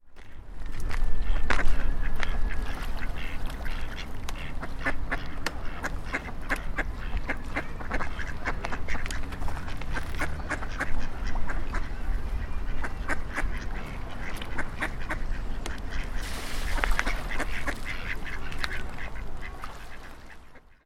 Ducks